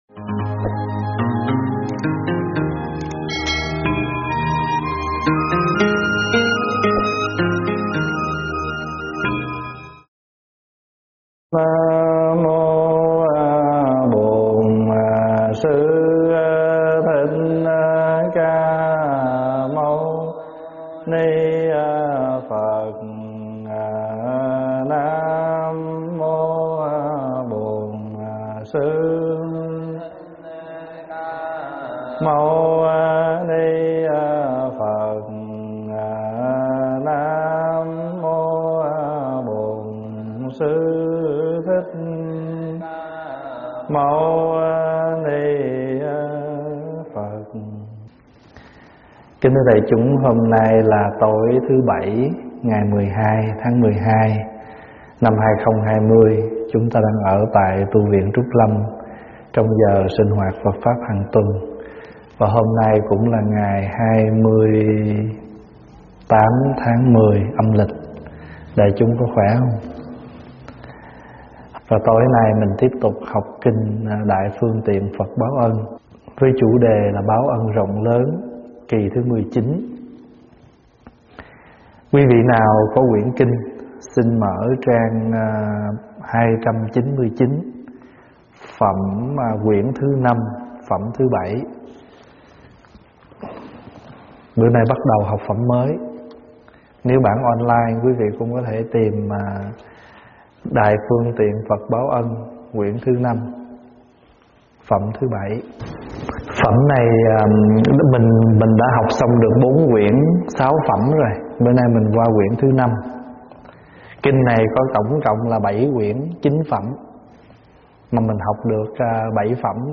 pháp thoại Báo Ân Rộng Lớn 19
giảng tại Tv Trúc Lâm